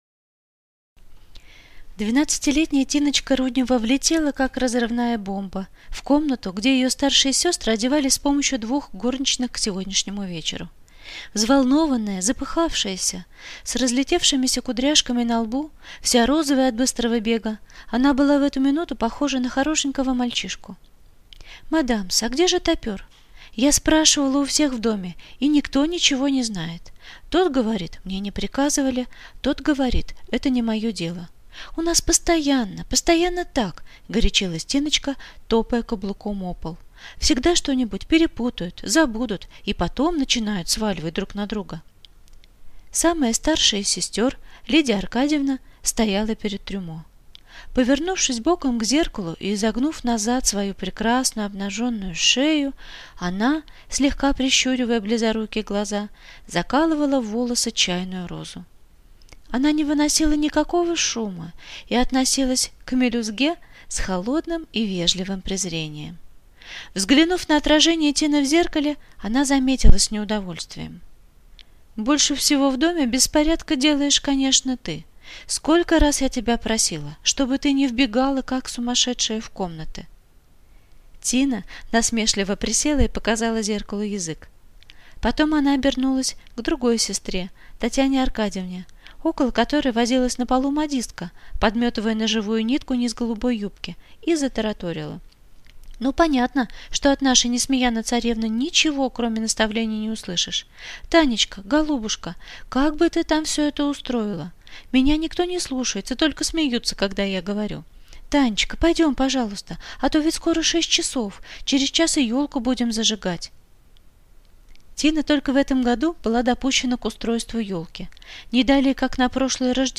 Аудиокнига Тапер | Библиотека аудиокниг